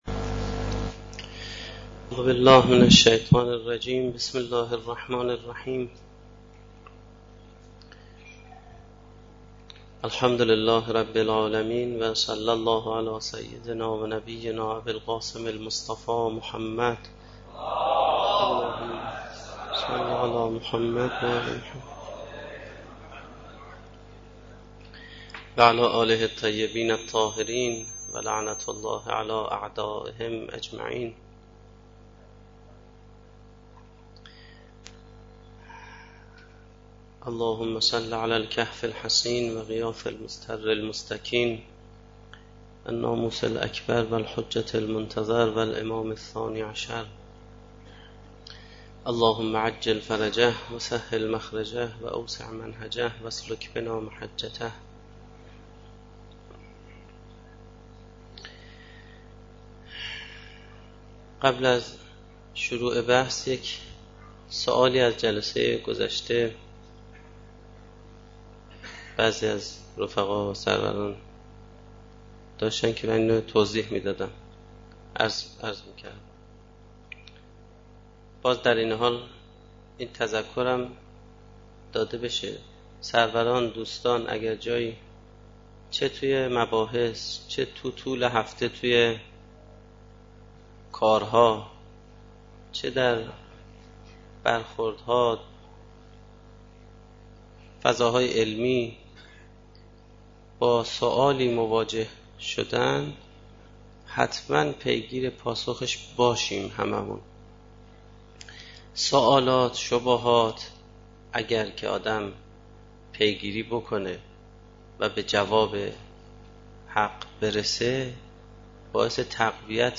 سخنرانی
در جلسه سیر و سلوک قرآنی